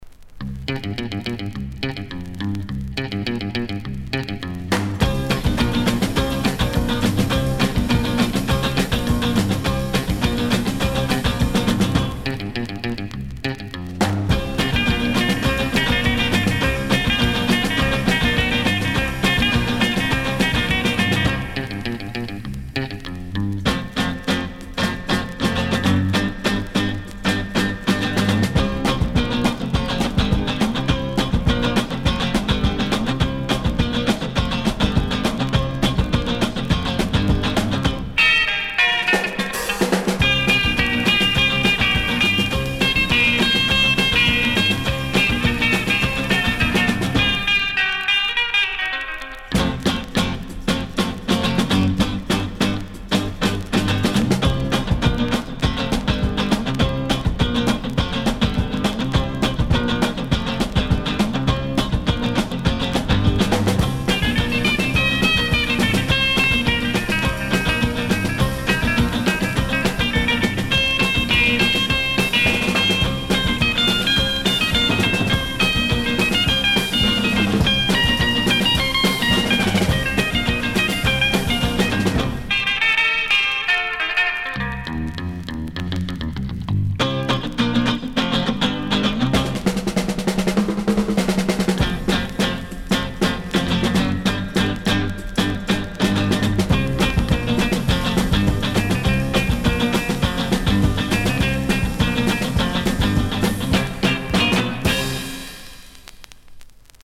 Винил